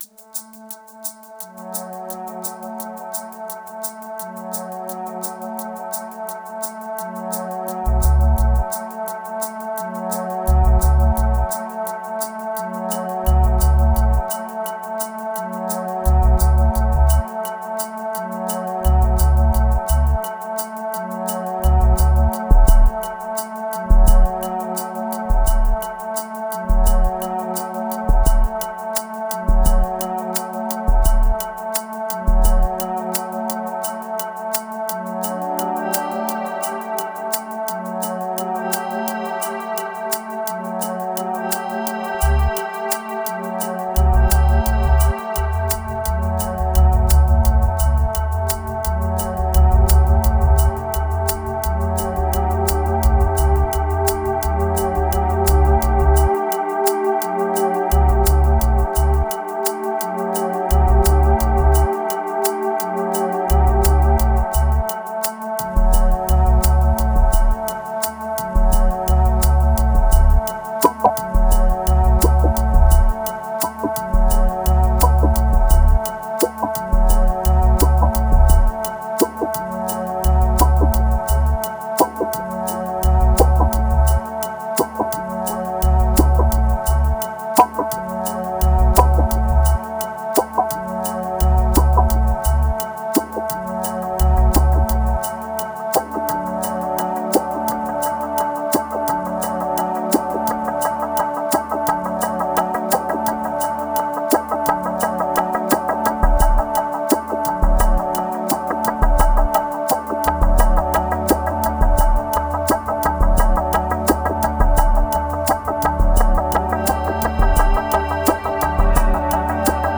815📈 - 10%🤔 - 86BPM🔊 - 2020-07-18📅 - 221🌟
Healing Dub Visions Pills Mental Drift Sampler Trigger